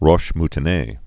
(rôsh mtn-ā, mtô-nā)